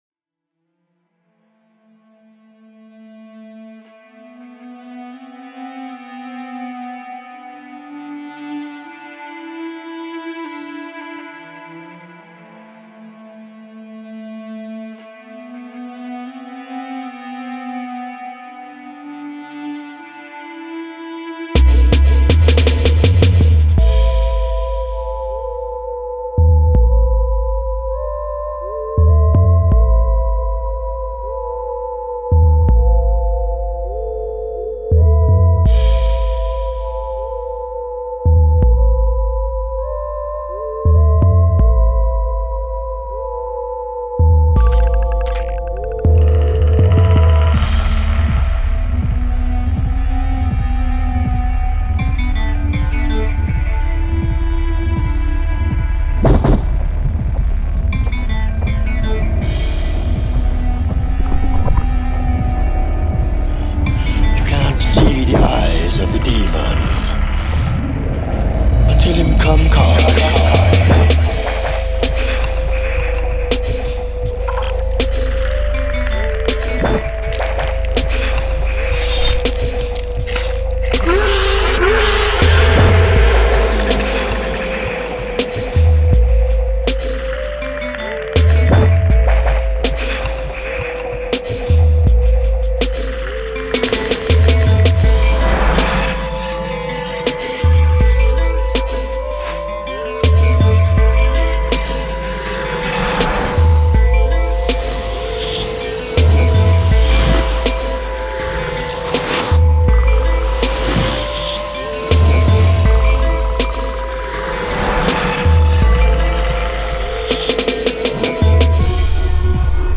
Some inserts taken from the first three films - Predator One, Predator Two & Predators.